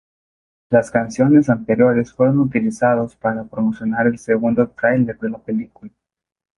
Pronúnciase como (IPA)
/ˈtɾaileɾ/